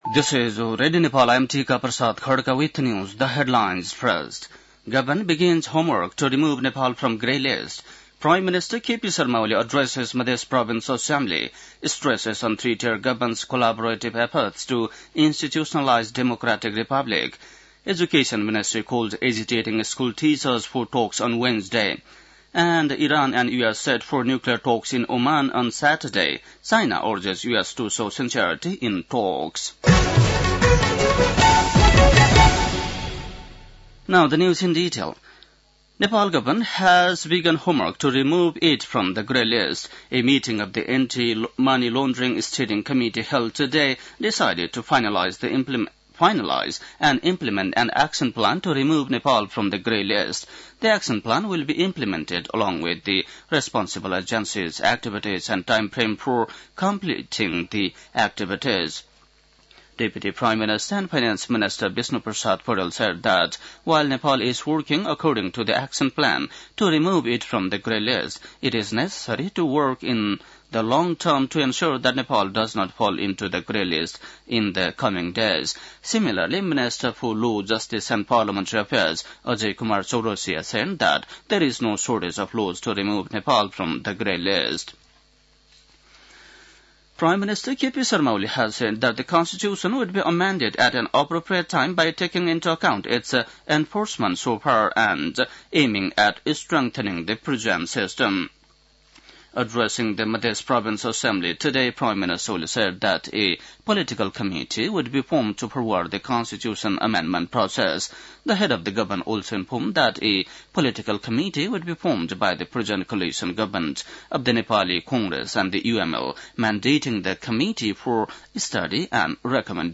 बेलुकी ८ बजेको अङ्ग्रेजी समाचार : २६ चैत , २०८१